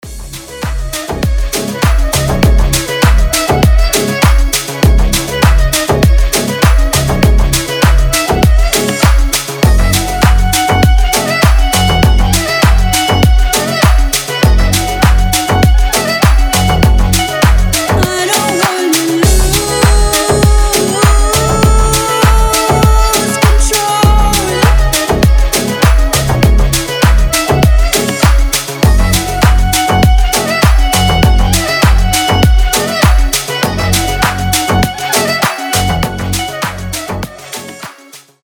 • Качество: 320, Stereo
deep house
восточные
дудук
Красивая музыка с восточным мотивом